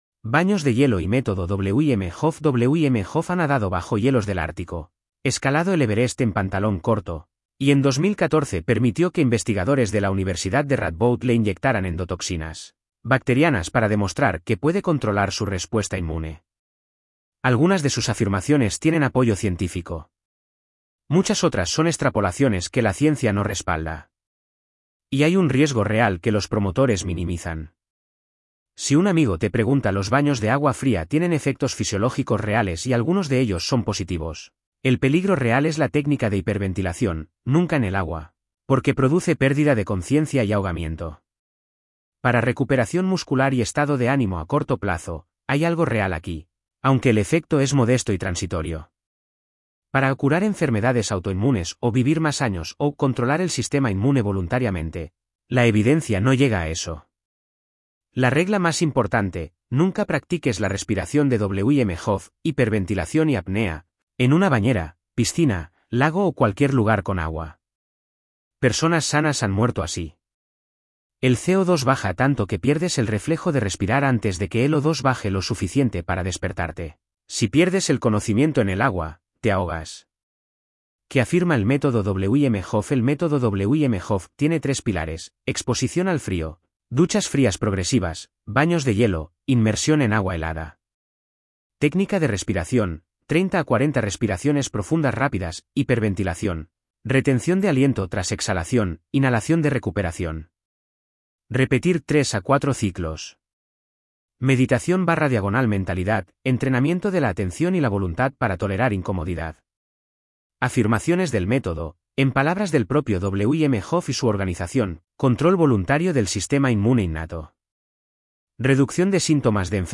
Voz: Microsoft Alvaro (es-ES, neural). Descargar MP3.